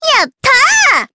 Miruru-Vox_Happy4_jp.wav